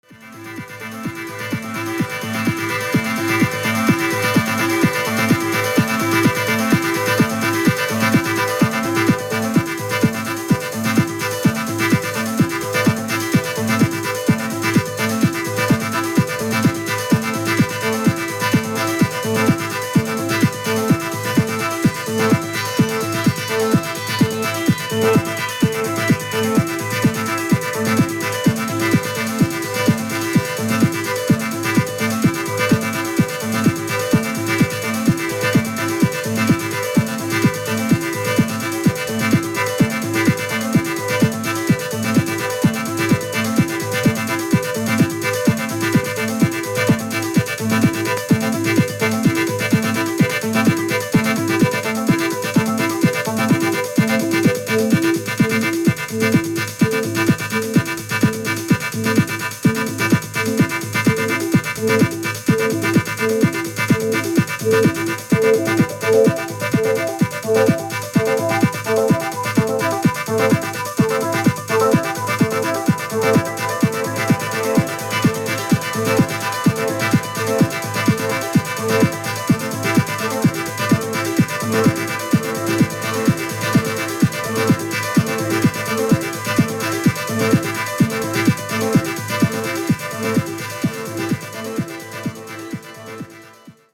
進化したACID,DEEP HOUSE感たまんないですね！！！